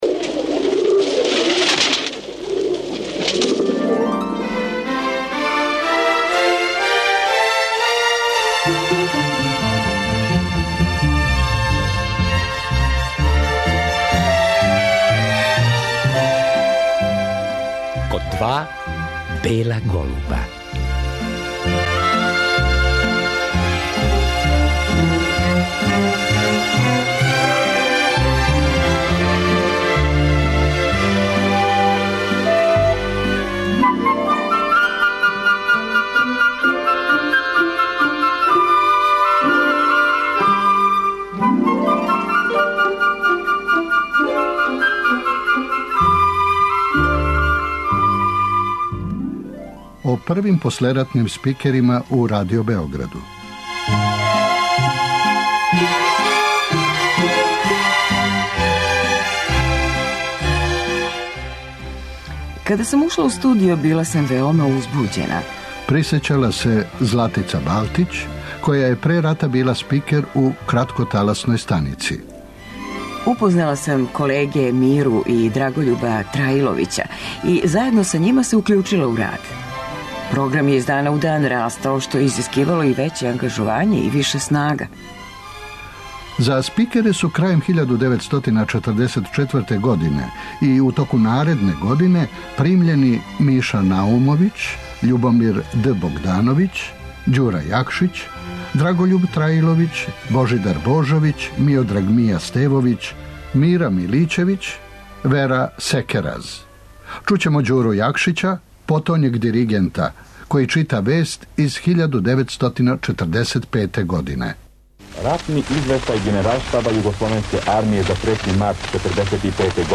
Реприза
Поводом Дана Радио Београда, који се обележева 1. октобра, у вечерашњој емисији слушаћемо архивске снимке првих послератних спикера Радио Београда.